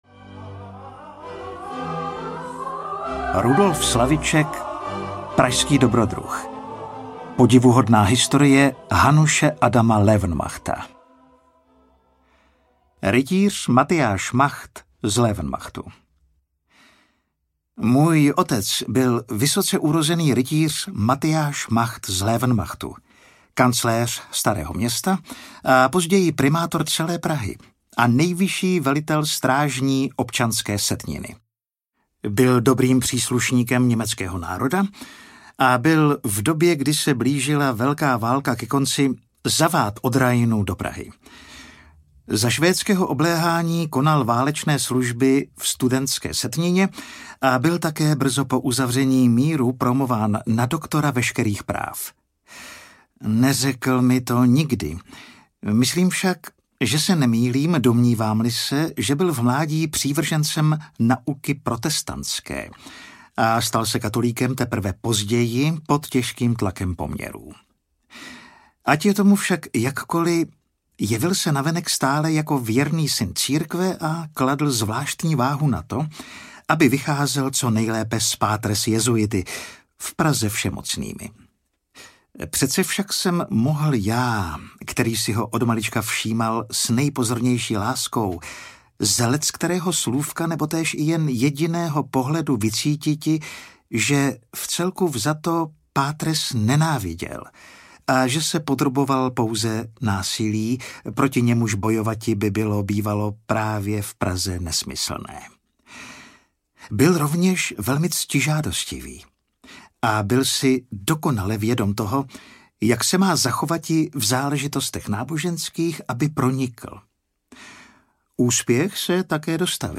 Ukázka z knihy
Hudba: Johann Sebastian Bach, Antonio Vivaldi a Edvard Grieg | Nahráno ve Studiu Virtual v roce 2025